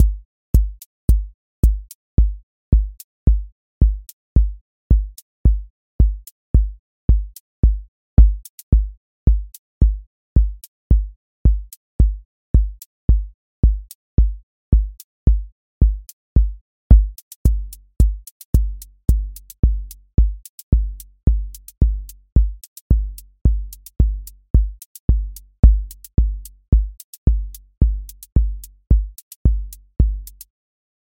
Four on Floor QA Listening Test house Template
voice_kick_808 voice_hat_rimshot voice_sub_pulse